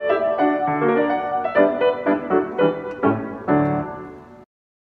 Augmented Triad
mp3Aulin, Valborg, Op. 8, 7 Piano Pieces, No. 7 Fantasi, mm.144-148